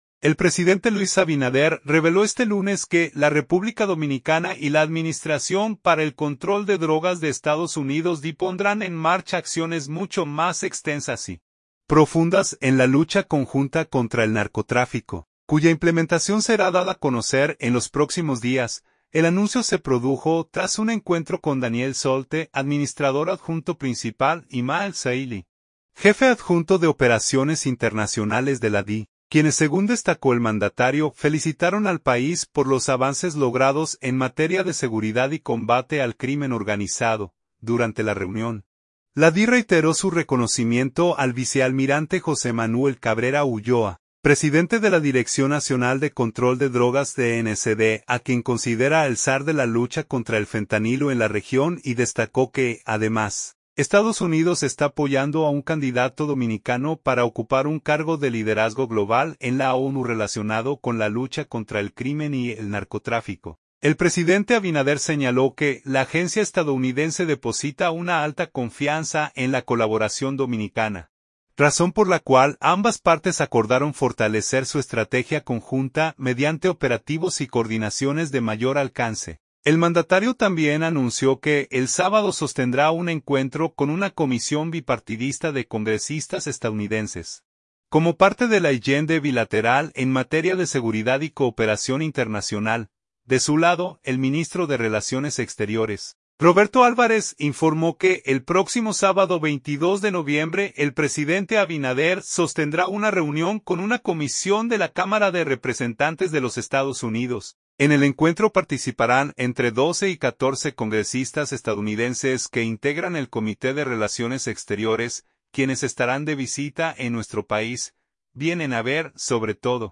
El presidente Abinader ofreció estas declaraciones durante el encuentro LA Semanal con la Prensa que se realiza cada lunes con los medios de comunicación en el Salón Las Cariátides del Palacio Nacional.